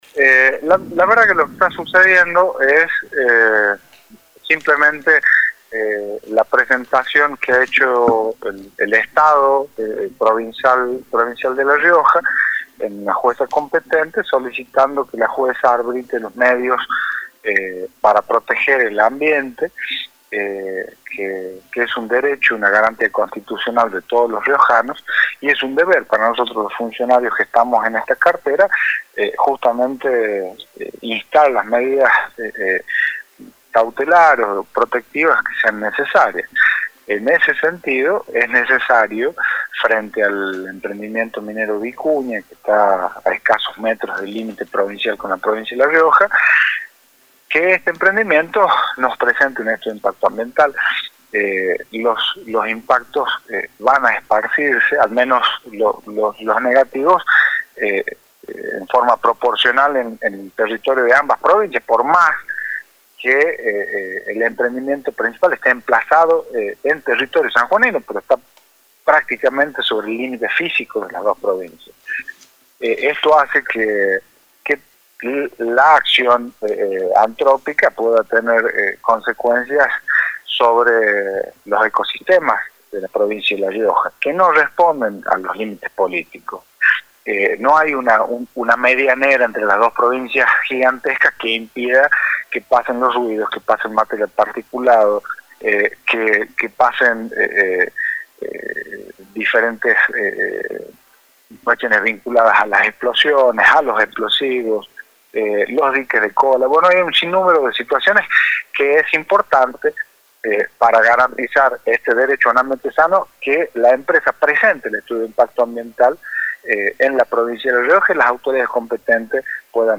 En la entrevista brindada al programa Nueva Época de Radio Libertad, Santiago Azulay, Secretario de Ambiente de la Provincia, analizó el reciente fallo judicial que ordena medidas de protección ambiental.